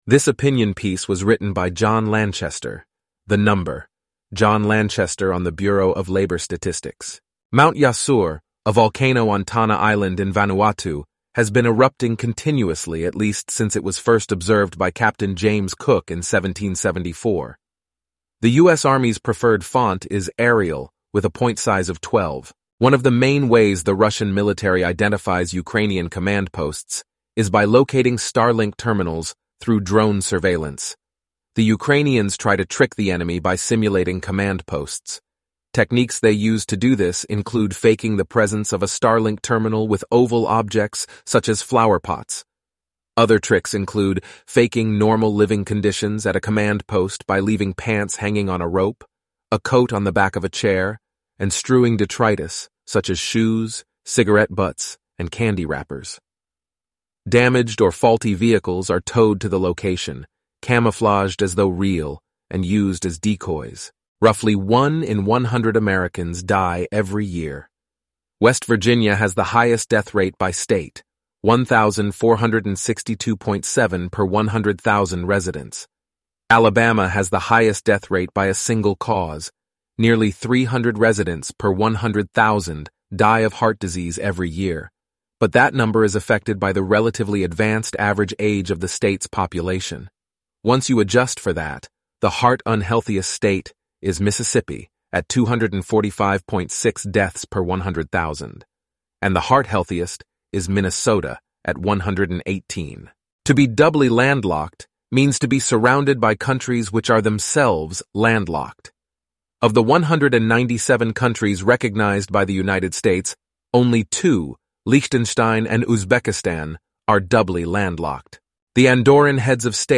eleven-labs_en-US_Antoni_standard_audio.mp3